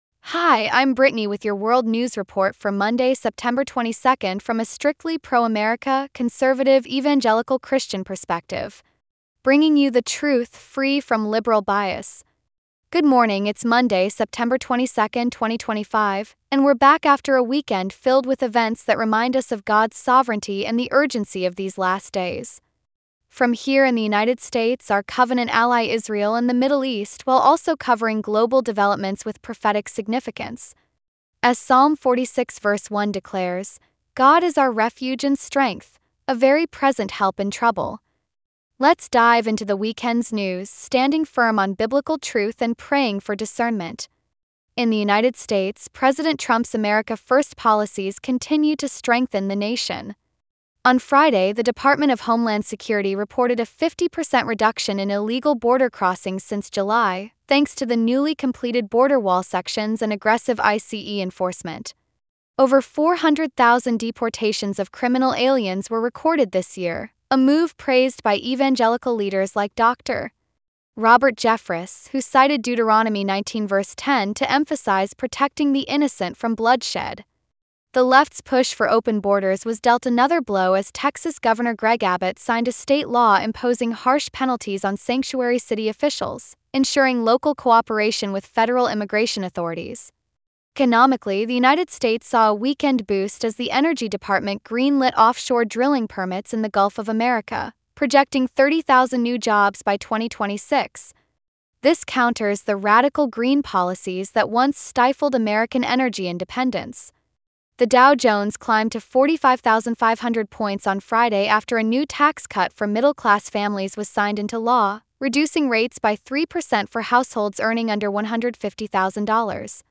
World News Report For Monday, September 22, 2025